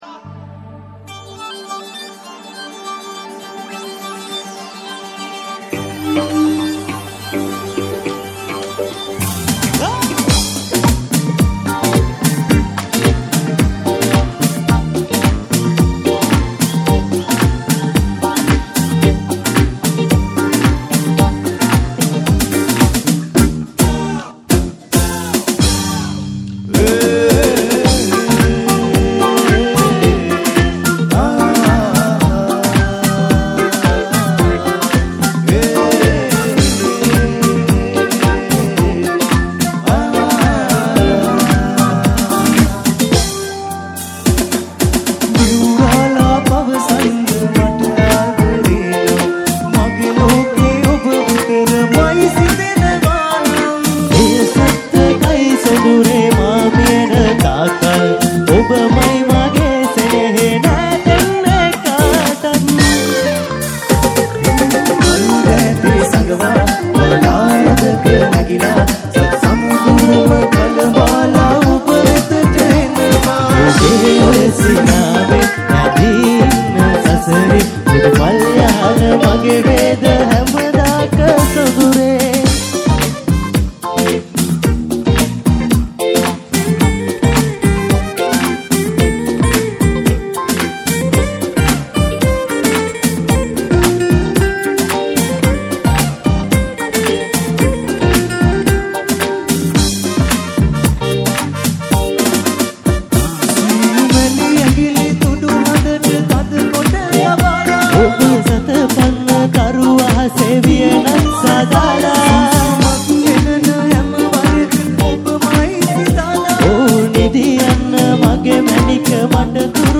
Srilanka No.1 Live Show Download Site...